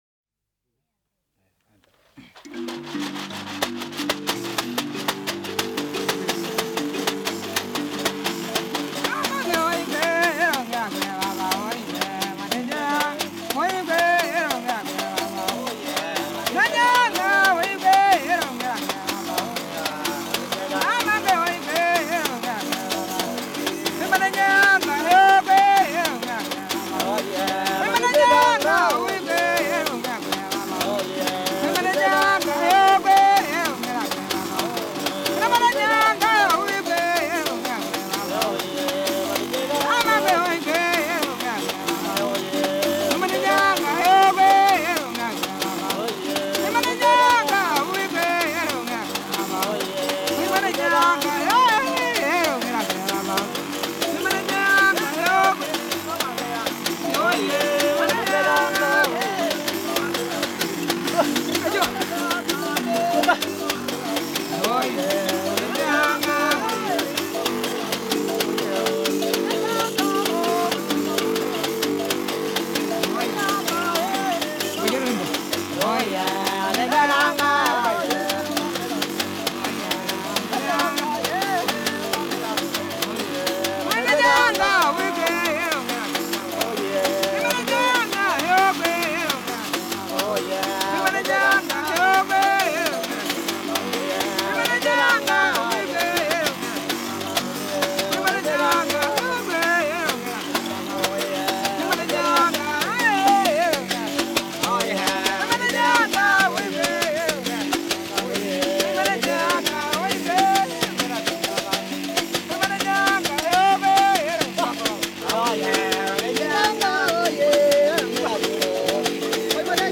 CANTI E MUSICHE CERIMONIALI DA UGANDA, KENYA E TANZANIA